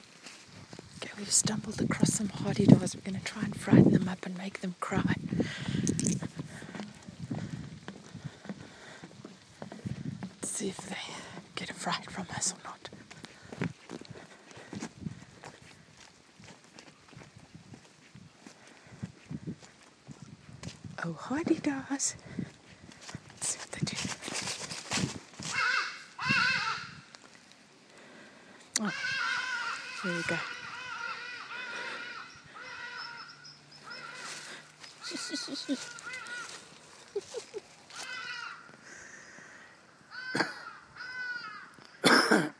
Hadeda Boo
These noisy birds are our wake-up alarm every morning. Have wanted to record their call for ages. Finally got the opportunity to do so on a walk in Wakkerstroom.